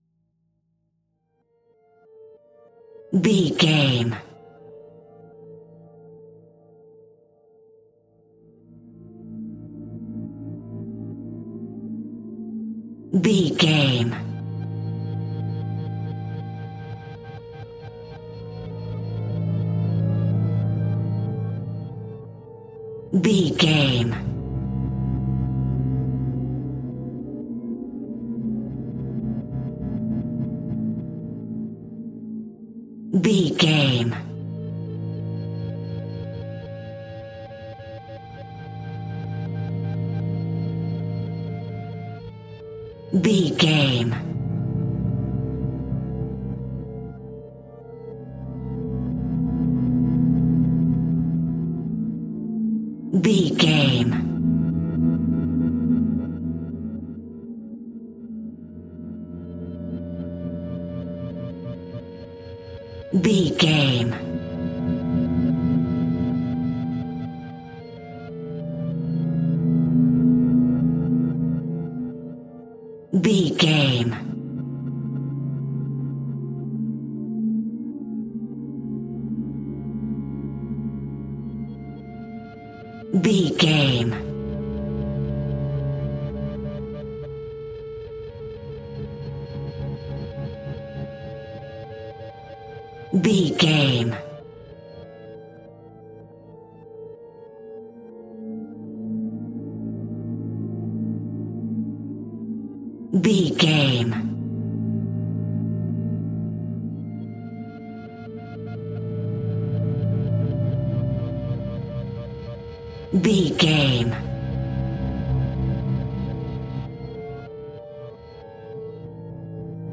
Atonal
Slow
tension
ominous
haunting
eerie
synthesiser
strings
ambience
pads